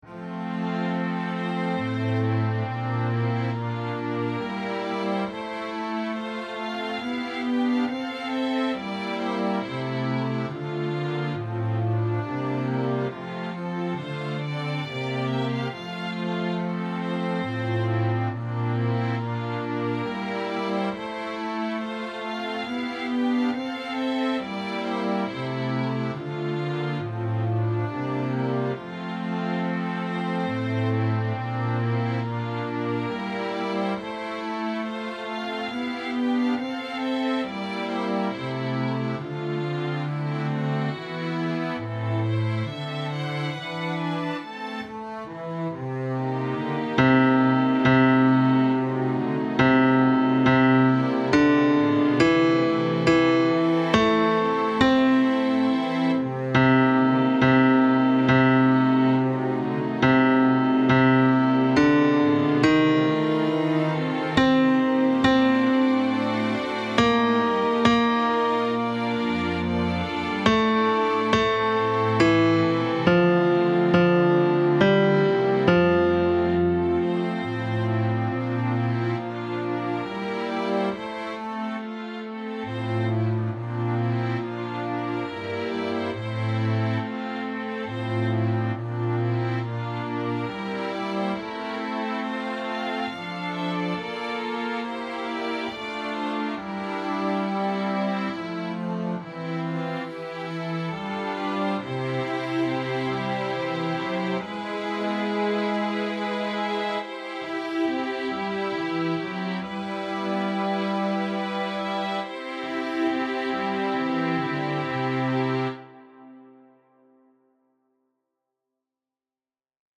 -Material didáctico-
Bajo I
5.-Agnus-Dei-BAJO-I-MUSICA.mp3